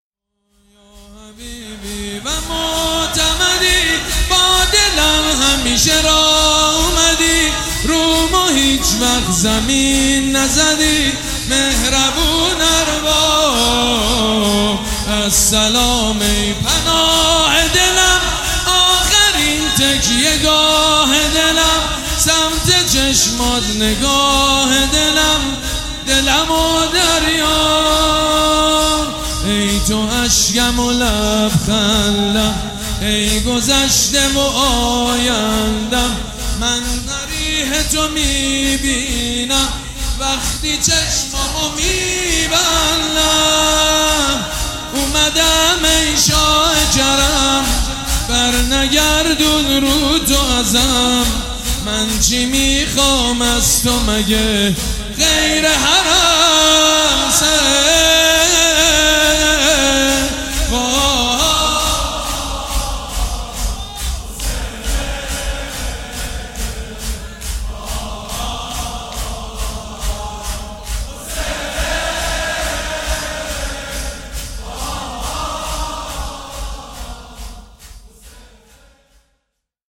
شور زیبا